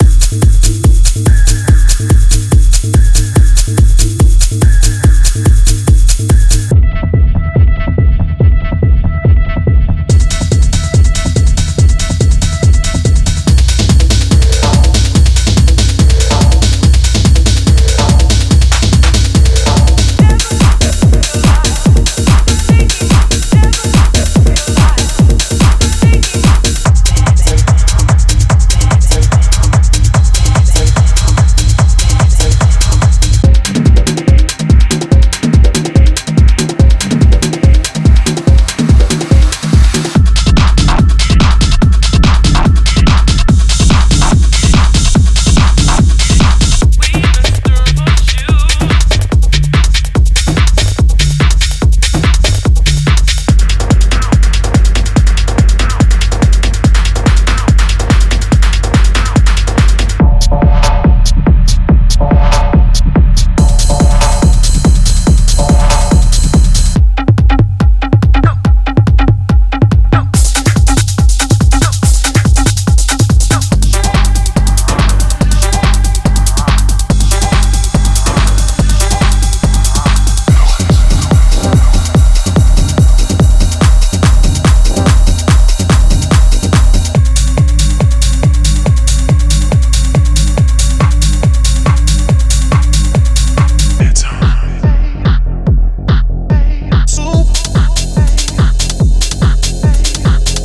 デモサウンドはコチラ↓
Genre:Techno
132 Beat Loops